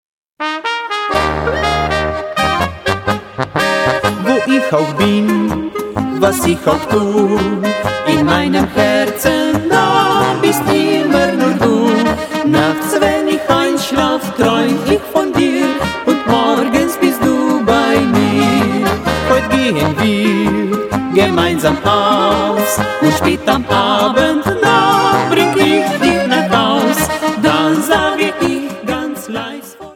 Genre: Oberkrainer Musik